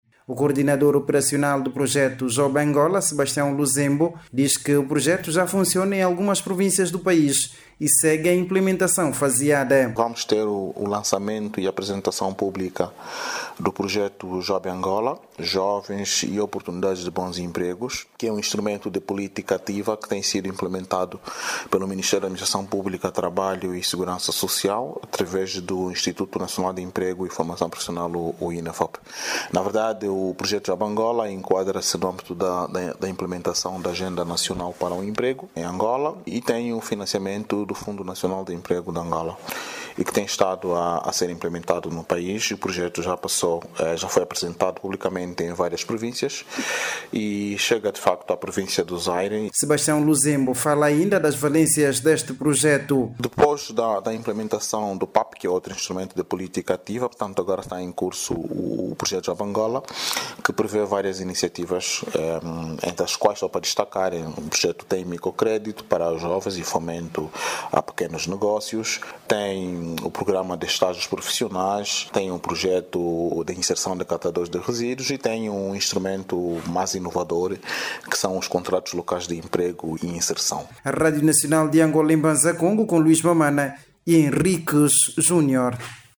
O Ministério da Administração Pública, Trabalho e Segurança Social, lança hoje, segunda-feira(25), na Cidade de Mbanza Kongo, Zaire, o projecto de empregabilidade para jovens. O projecto, vai possibilitar a criação muitos empregos e vai ser distribuído kits de trabalho para cooperativas de jovens e mulheres. Clique no áudio abaixo e ouça a reportagem